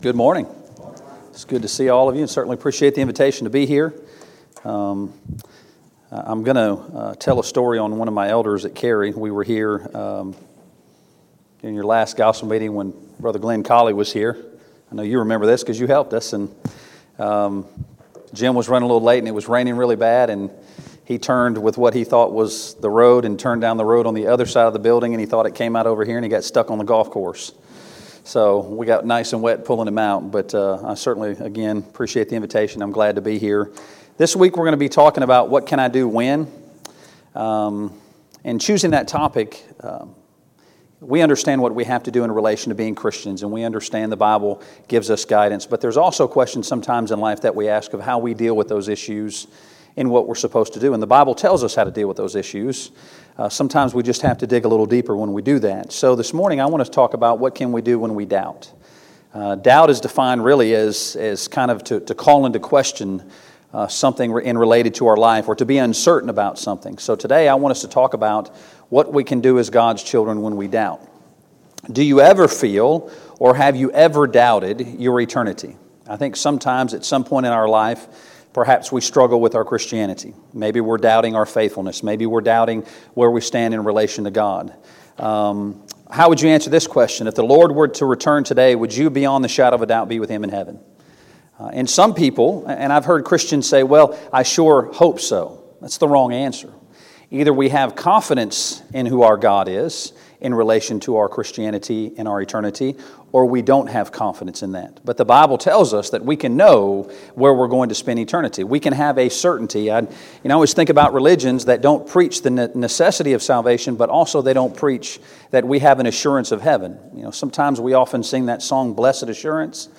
2019 Spring Gospel Meeting Service Type: Gospel Meeting Preacher